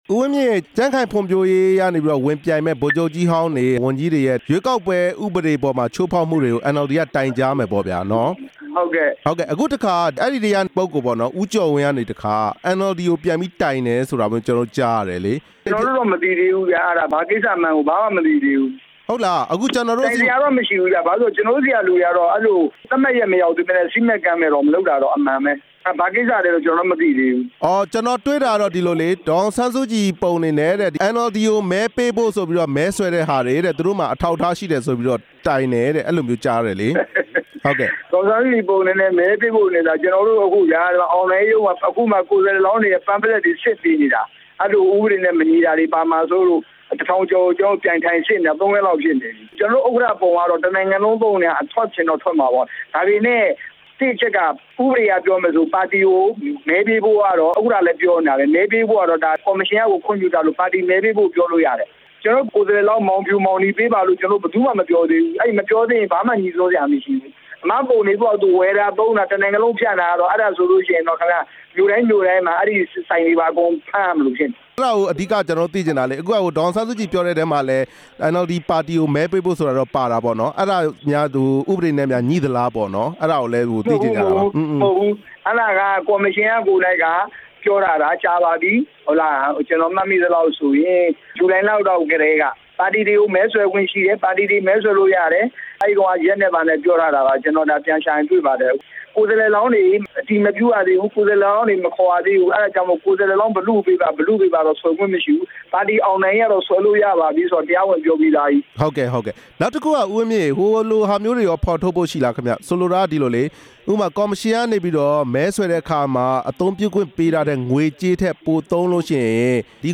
ဦးဝင်းမြင့်ကို မေးမြန်းချက်